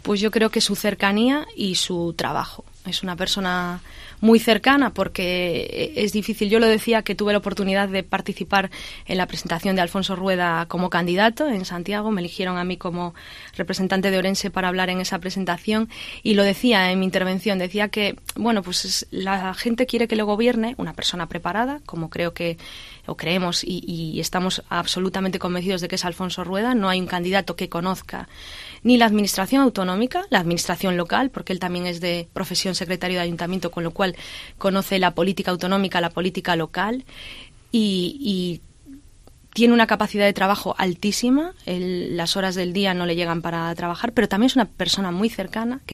en los estudios de Cope Ourense